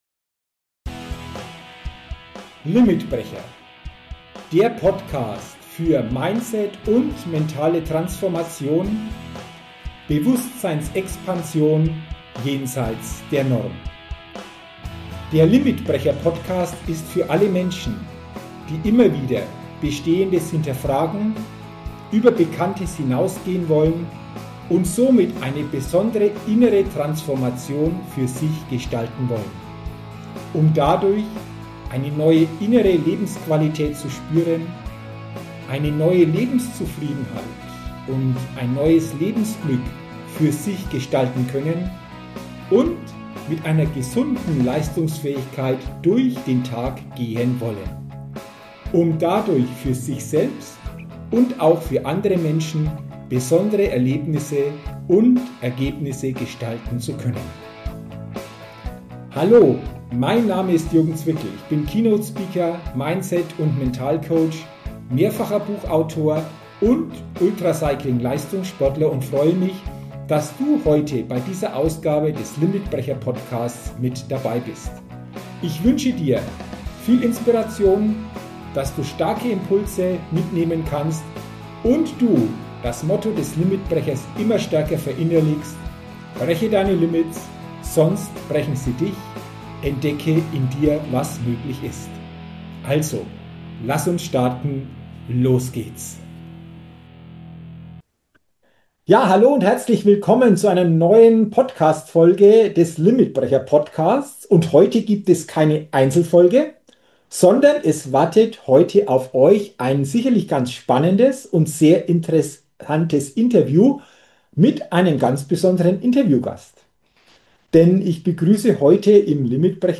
Dieses Gespräch ist eine Einladung an dich: Mut zu fassen.